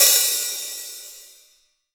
Index of /90_sSampleCDs/AKAI S6000 CD-ROM - Volume 3/Hi-Hat/12INCH_LIGHT_HI_HAT